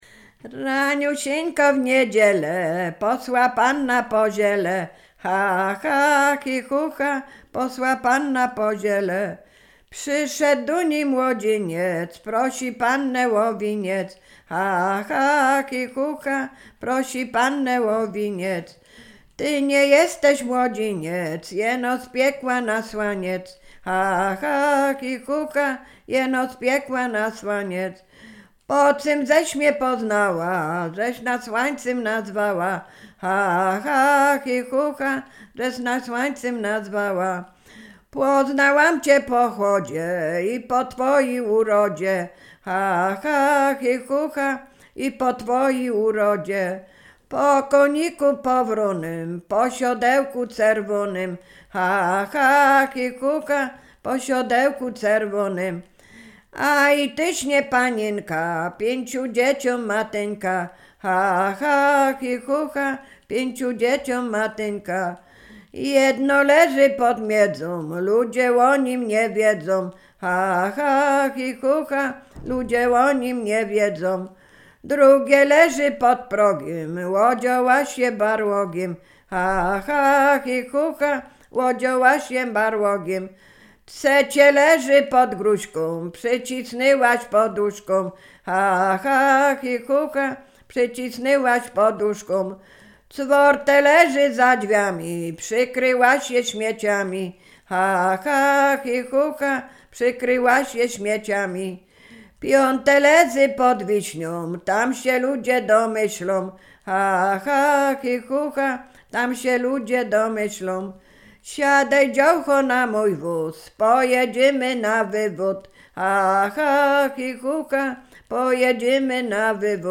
wojewódzctwo łódzkie, powiat sieradzki, gmina Brzeźnio, wieś Kliczków Mały
Ballada
ballady dziadowskie